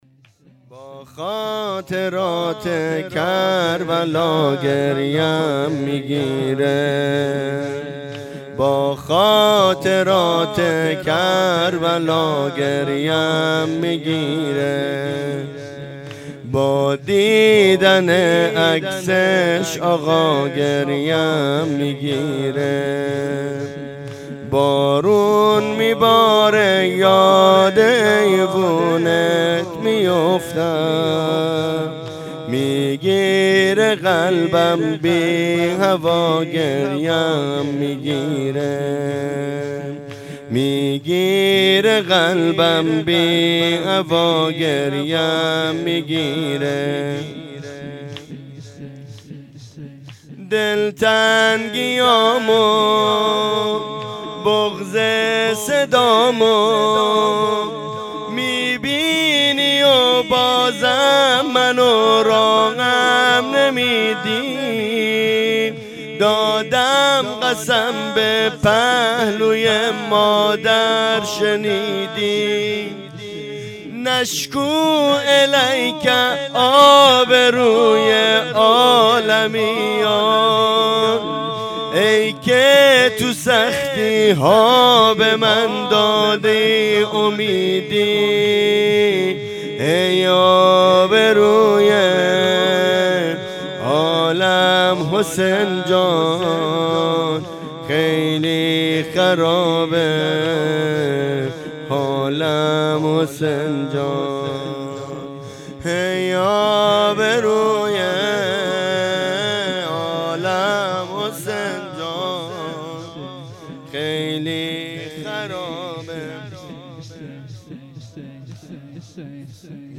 شهادت امام صادق علیه السلام 1404
شور پایانی با خاطرات کربلا گریم میگیره